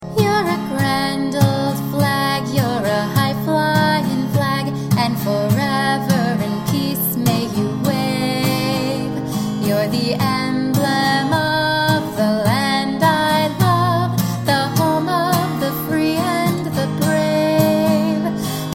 American Patriotic